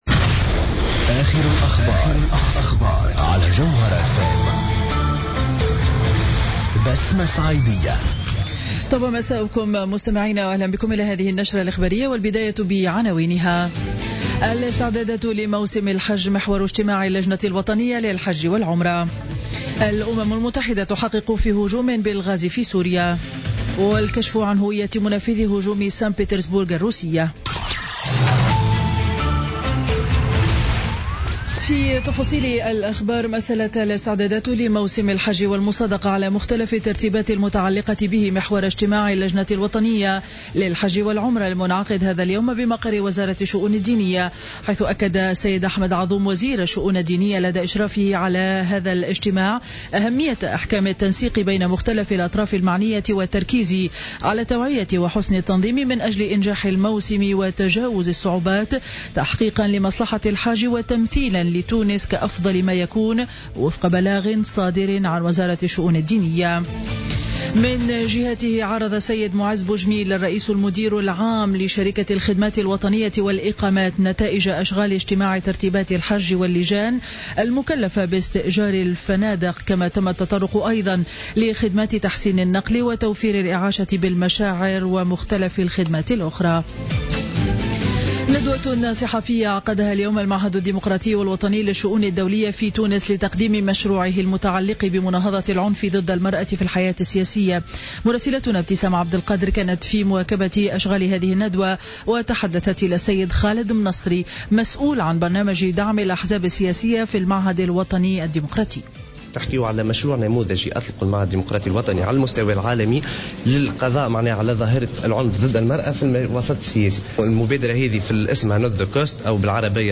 نشرة أخبار السابعة مساء ليوم الثلاثاء 4 أفريل 2017